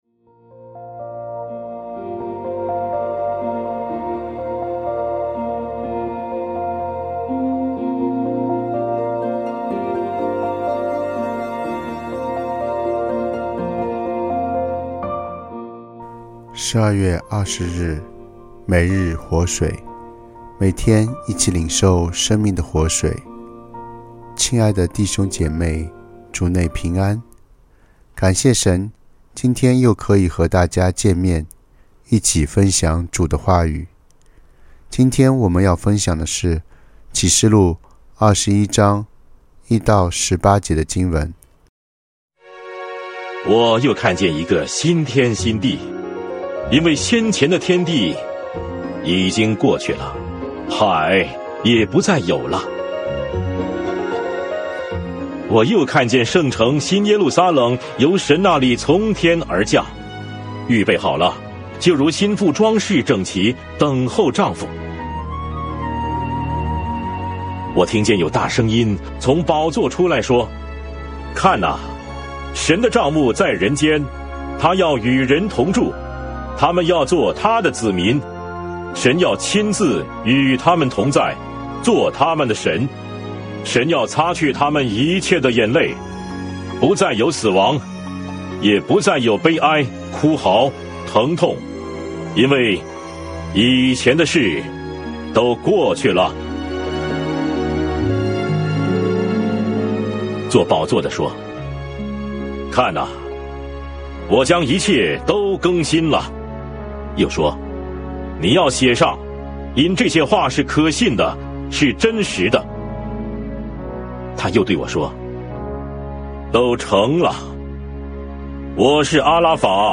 牧/者分享